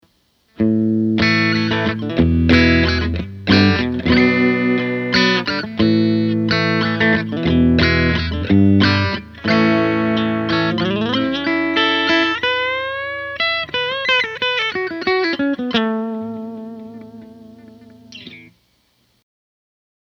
In any case, here are six versions of the same phrase with each different configuration: